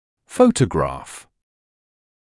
[‘fəutəgrɑːf][‘fəutəgrɑːf]фотография, фотоснимок